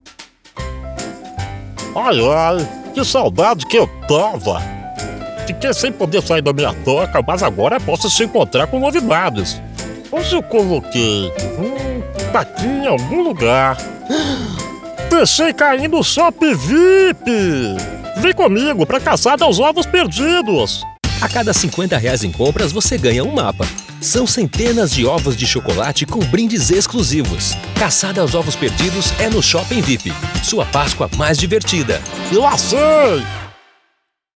Estilo(s): Padrão Animada Varejo
Locução comercial